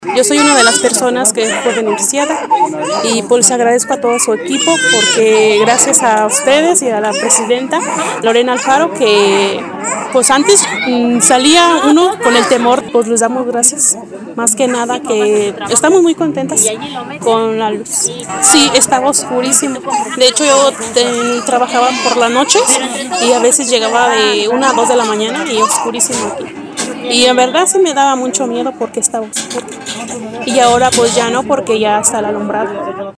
AudioBoletines
beneficiaria